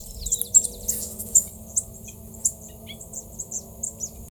Warbling Doradito (Pseudocolopteryx flaviventris)
Province / Department: Entre Ríos
Detailed location: Parque Escolar Rural Enrique Berduc
Condition: Wild
Certainty: Photographed, Recorded vocal